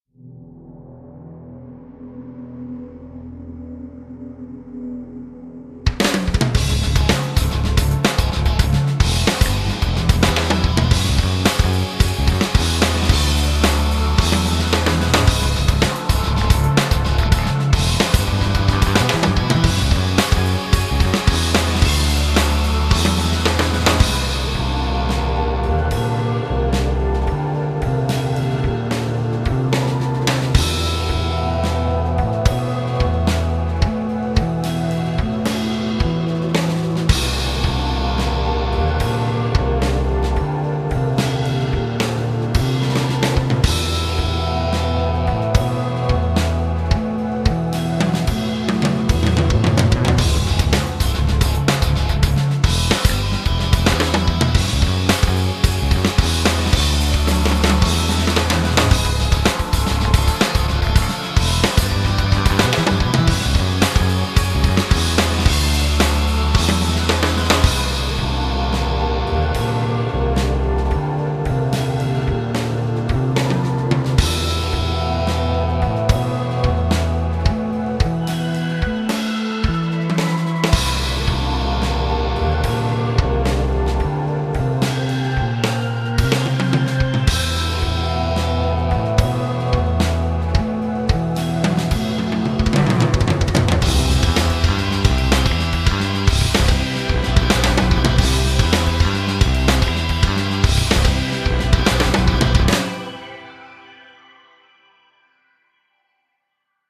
J'ai fait un test en utilisant les banques "LPC super performance" et "all chord 2" pour avoir tous les accords disponible :
cà fait un peu plus rock progressif car j'utilise des accords un peu bizarre :mdr: .
1progressivedemo.mp3